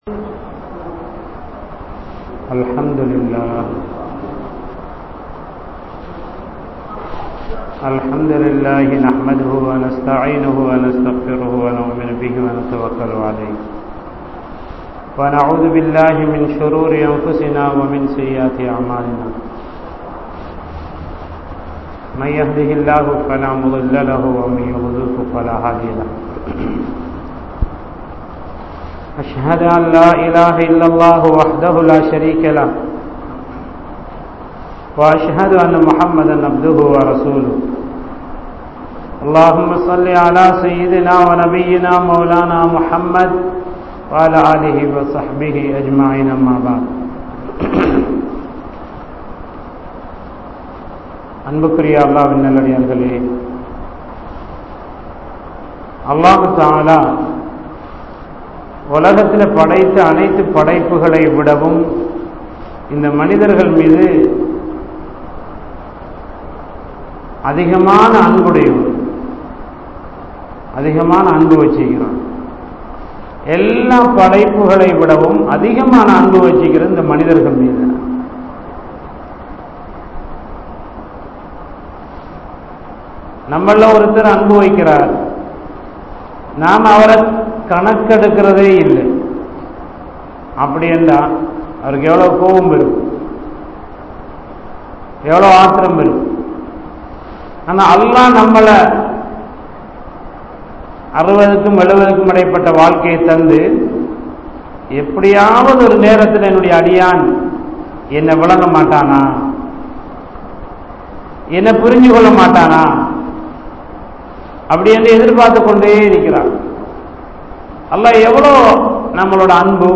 Allah Thanthulla Paakkiyangal (அல்லாஹ் தந்துள்ள பாக்கியங்கள்) | Audio Bayans | All Ceylon Muslim Youth Community | Addalaichenai
Masjithur Ravaha